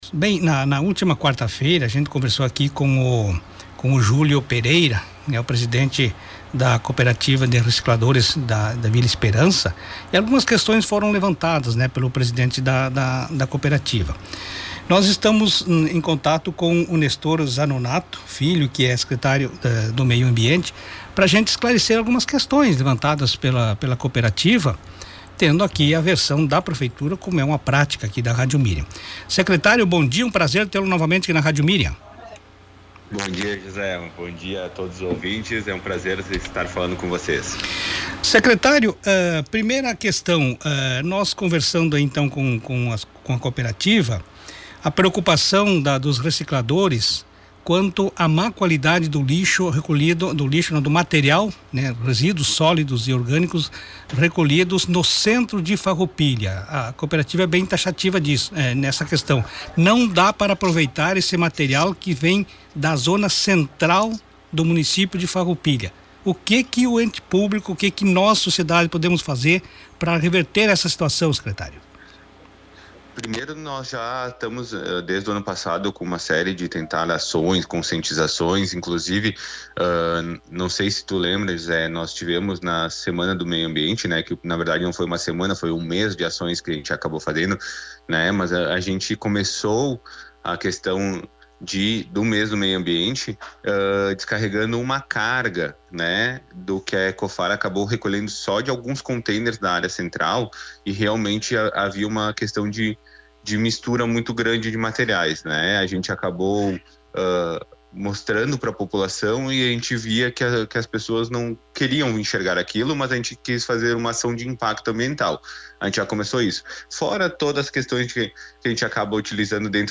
Secretário do Meio Ambiente de Farroupilha – Nestor Zanonatto Filho